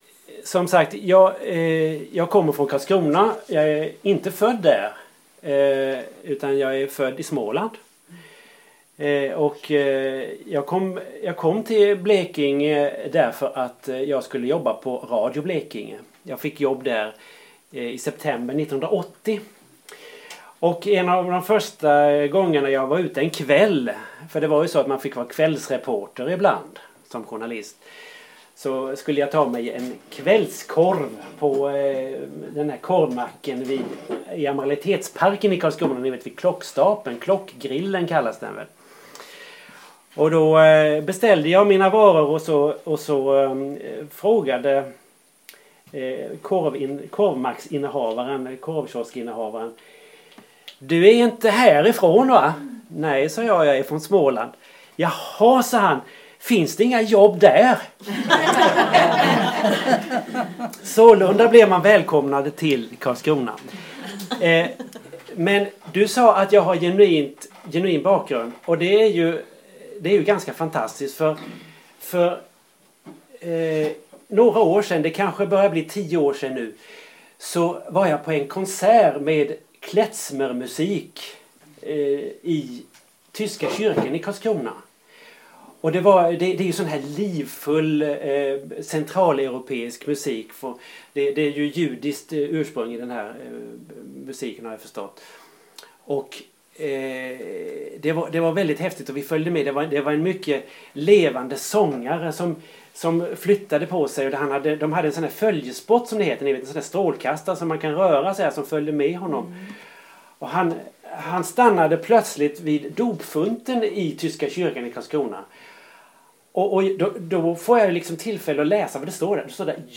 Inledning föredrag Skrivarklubben i Blekinge